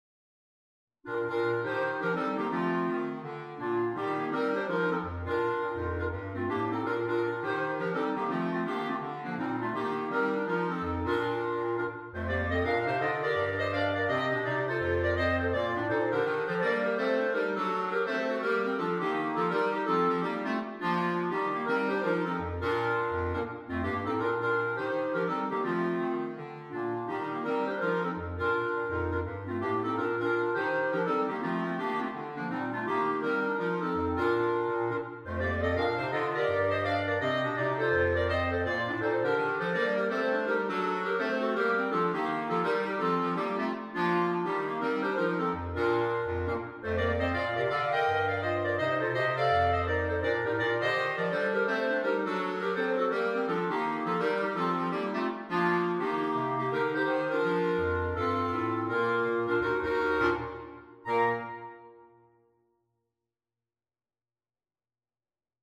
jazz arrangement for clarinet quartet